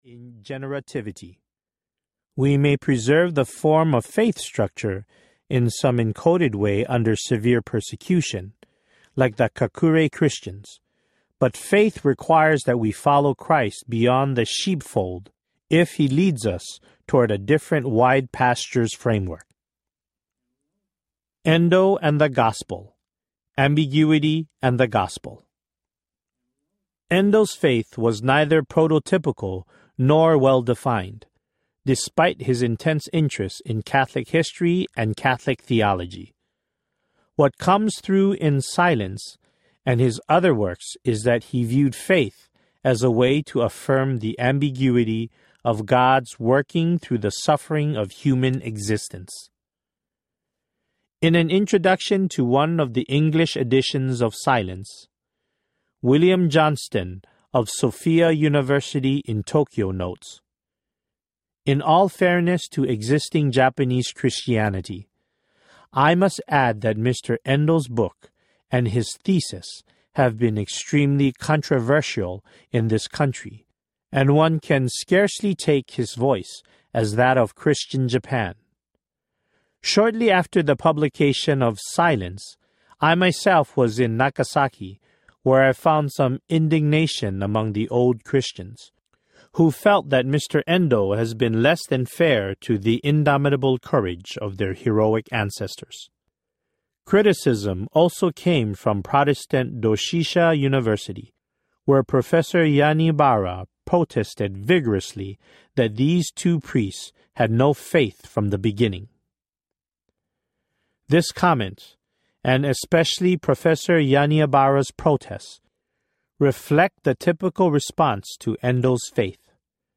Silence and Beauty Audiobook
9.75 Hrs. – Unabridged